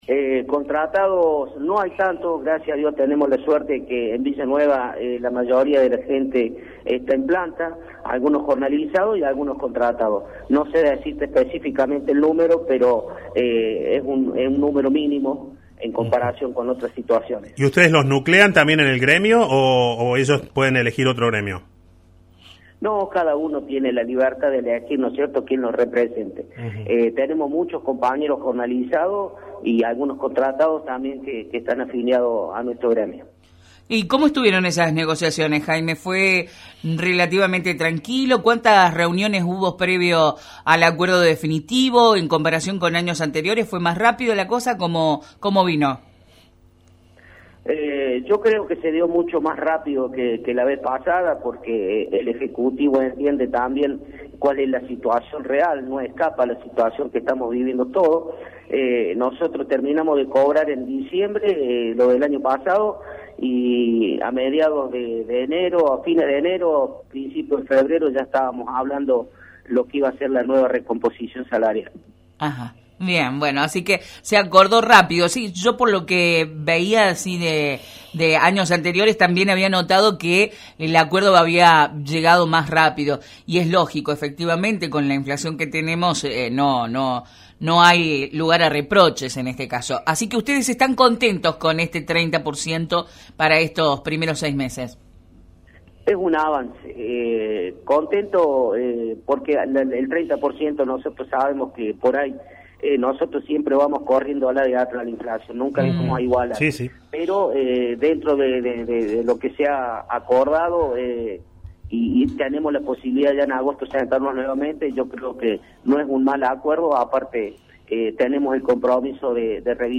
nota-municipales-vn.mp3